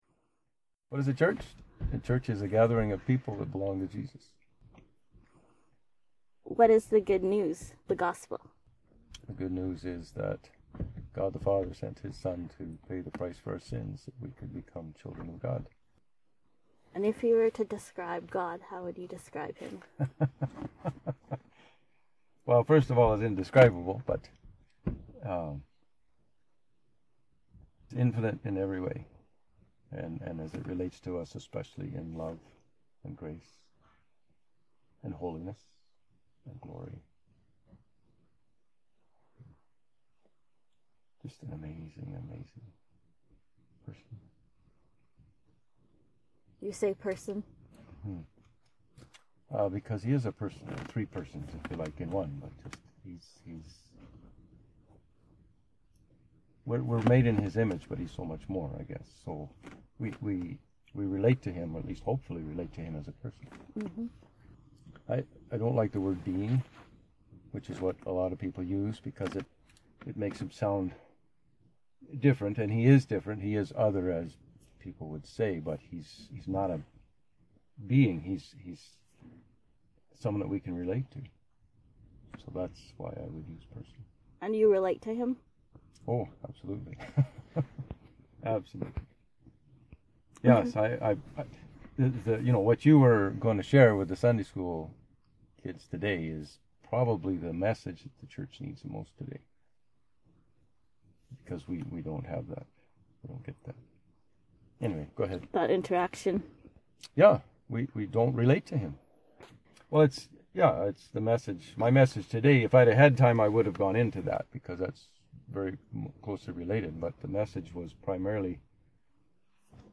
Questions People Might Ask, Conversation at the Corrals